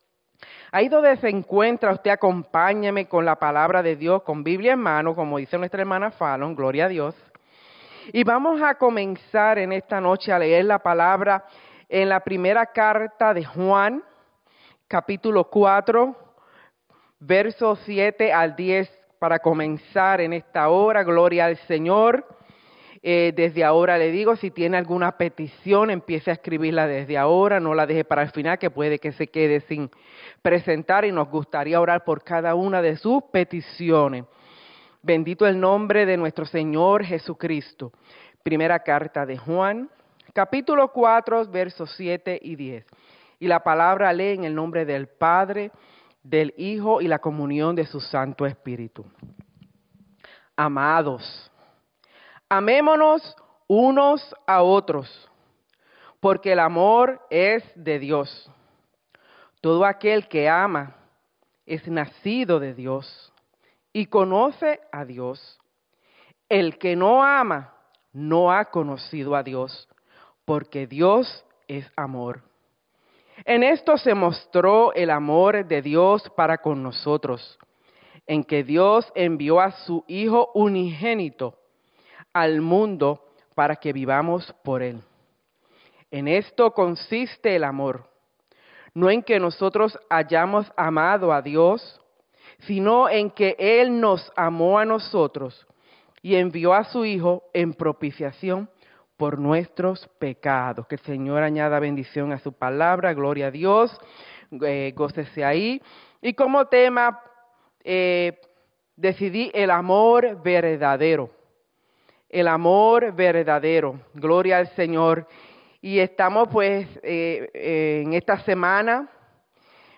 Iglesia Misión Evangélica
Predica